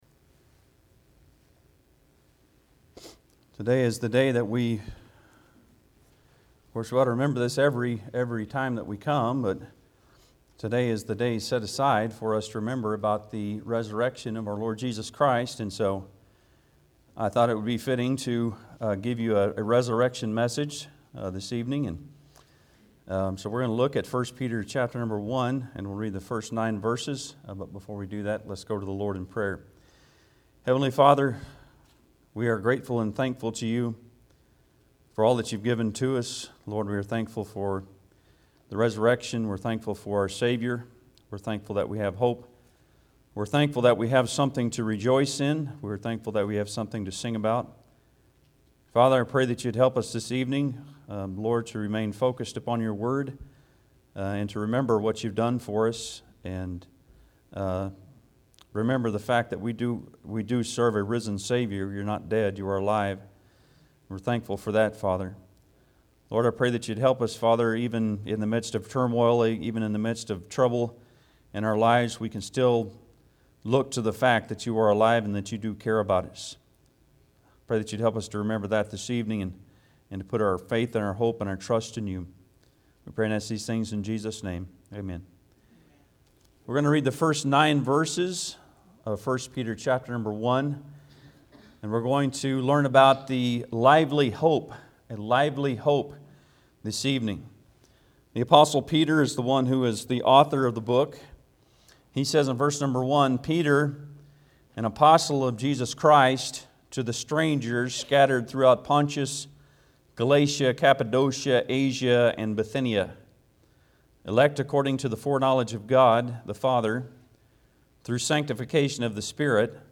I Peter 1:1-9 Service Type: Sunday pm Bible Text